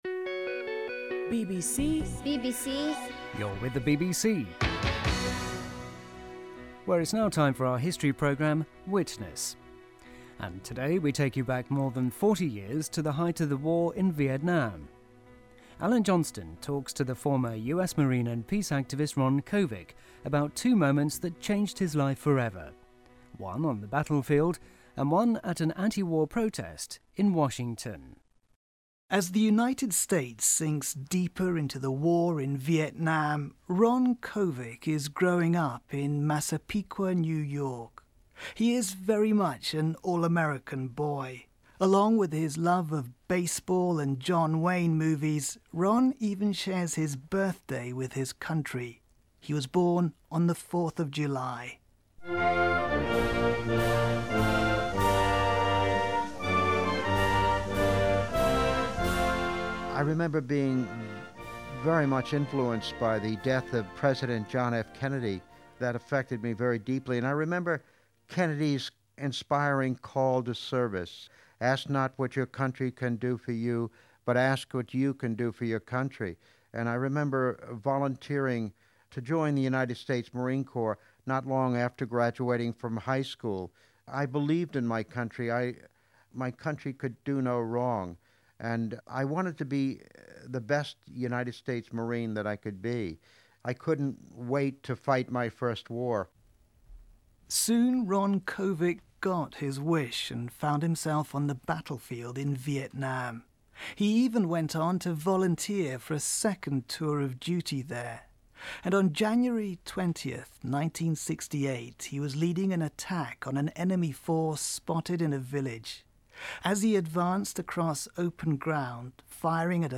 Ron Kovic BBC Interview
Please enjoy this BBC Interview of Ron Kovic